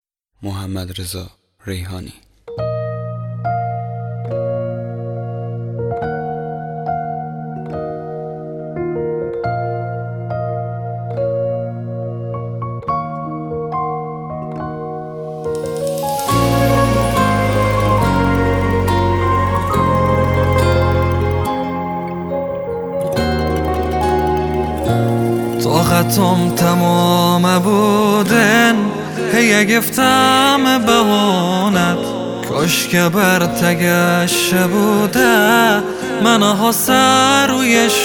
آهنگ بستکی
آهنگ بلوچی